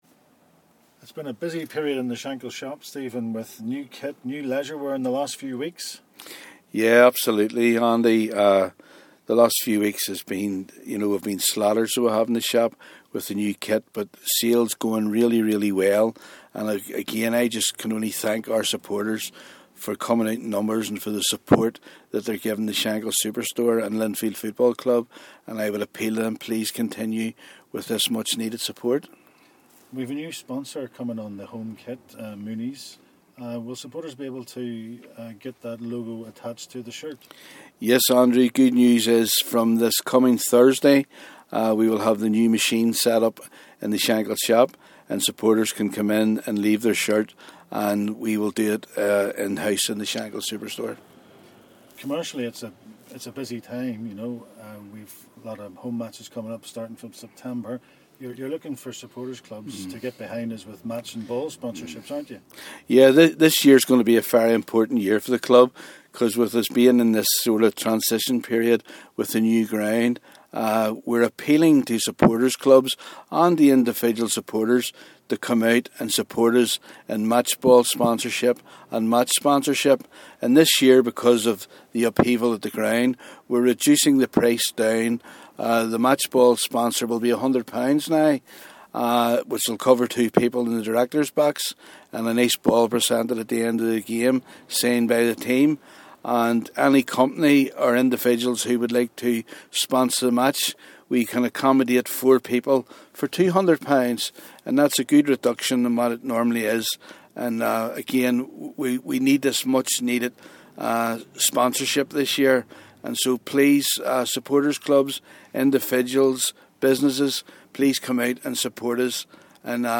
Commercial director in conversation